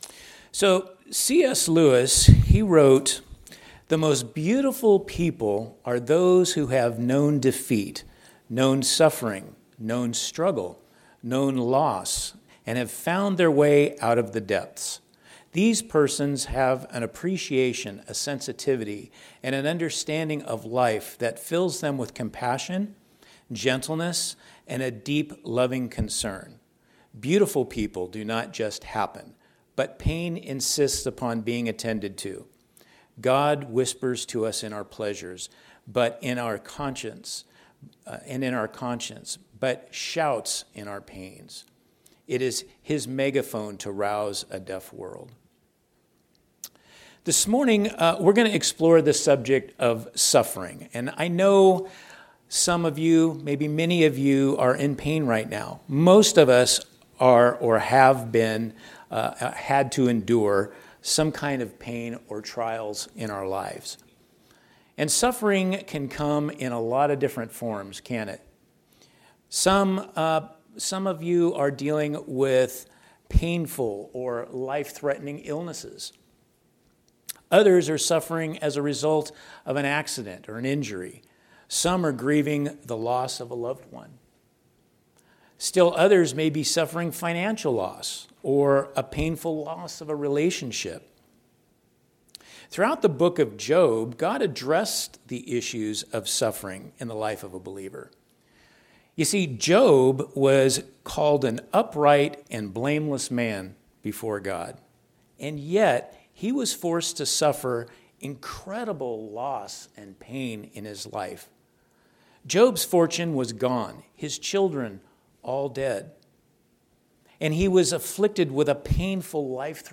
Teaching from Sunday AM service by Guest Speaker